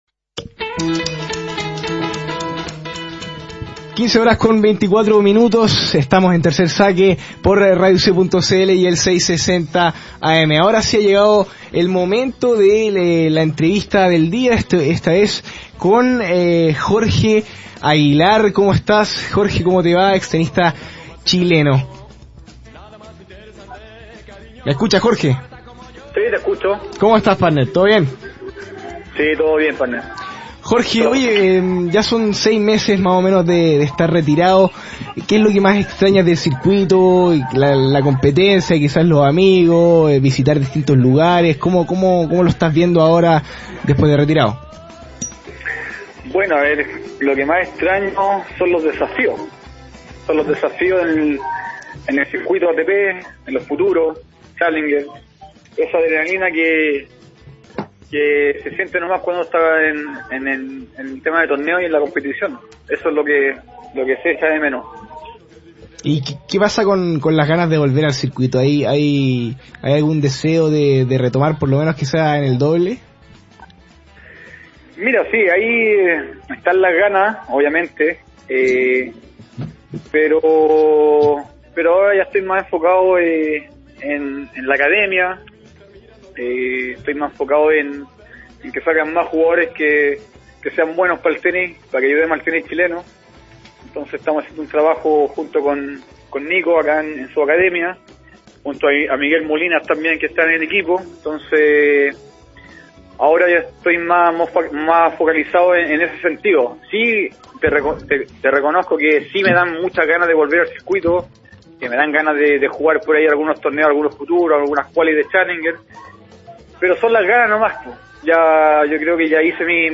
El retirado tenista habló de todo con el Tercer Saque el lunes 2 de mayo. Dijo que ve bien al tenis chileno, aunque criticó el trabajo en series menores. Además, se refirió a su futuro ligado al deporte blanco y habló del actual momento de la joven promesa nacional, Christian Garín.
Entrevista-Jorge-Aguilar.lite_.mp3